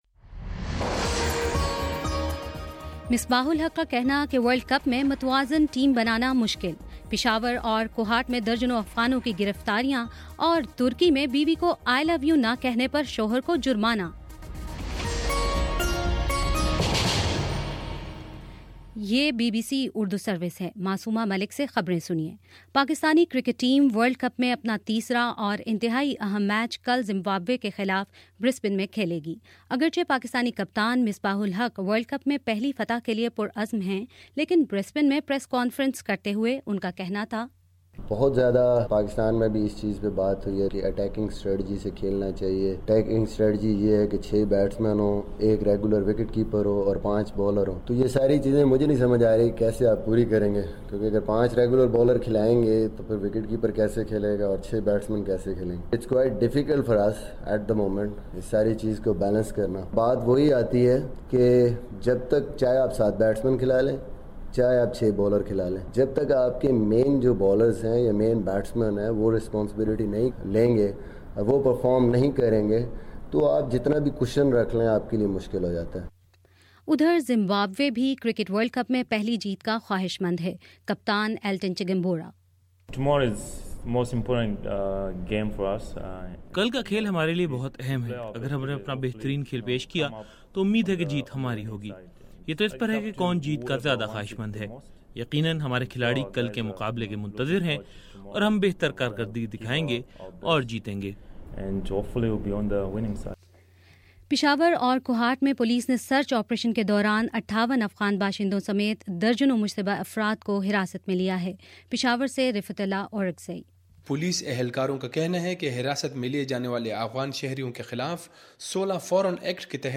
فروری28 : شام پانچ بجے کا نیوز بُلیٹن